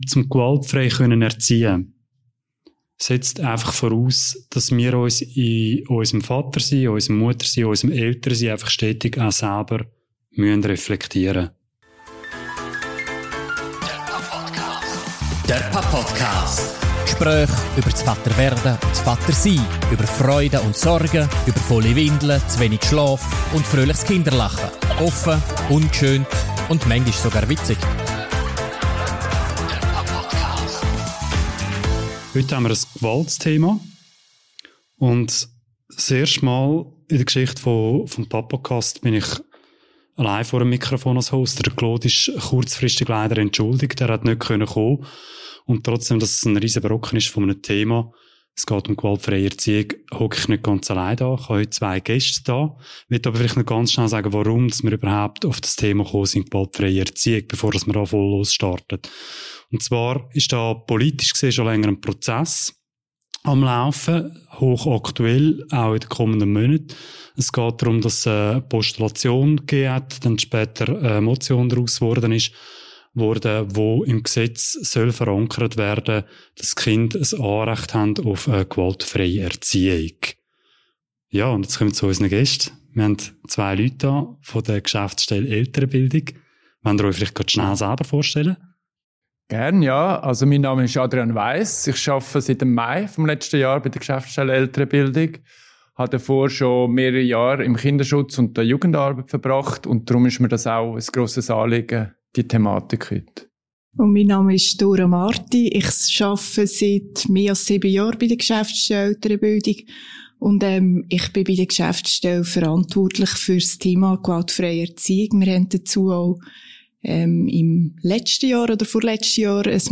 Zu dritt sprechen sie über die Ausprägungen von Gewalt an Kindern, die Stressfaktoren bei Eltern und über das Kursangebot der Elternbildung.